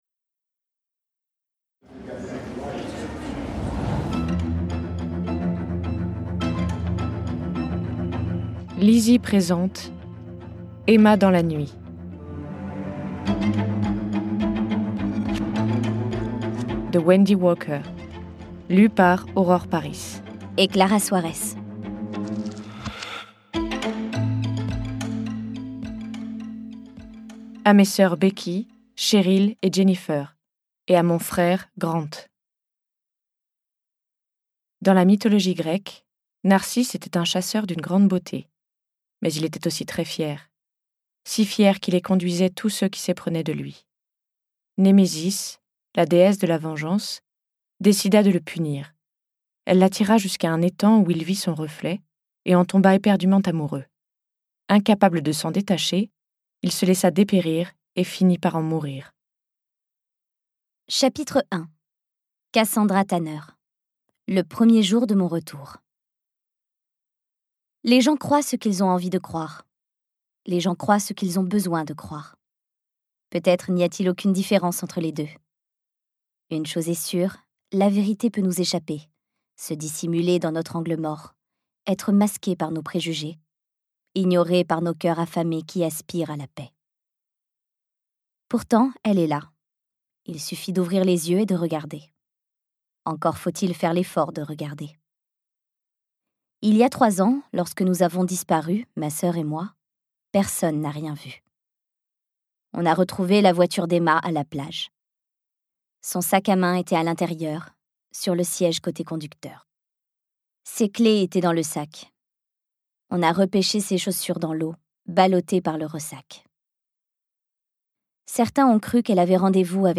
je découvre un extrait - Emma dans la nuit de Wendy WALKER